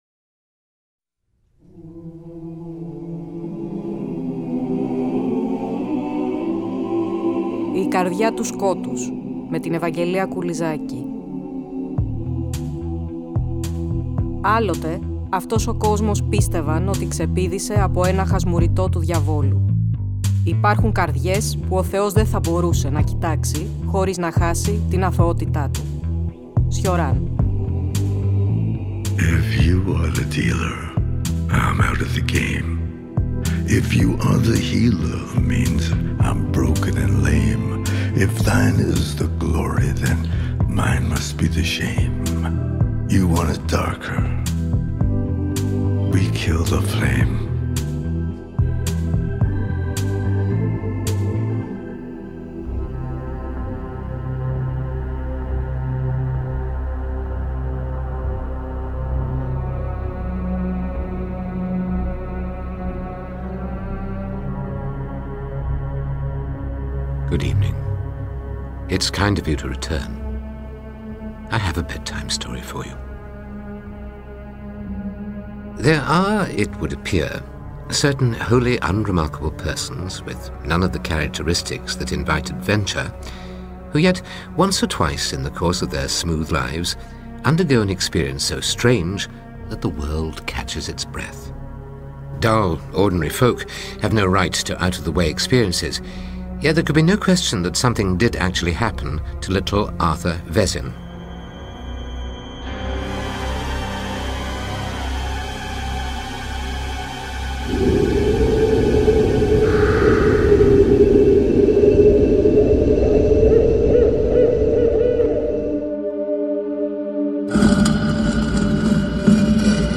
Ακούστε το 8ο επεισόδιο του νέου Κύκλου της εκπομπής, που μεταδόθηκε την Κυριακή 26 Οκτωβρίου από το Τρίτο Πρόγραμμα.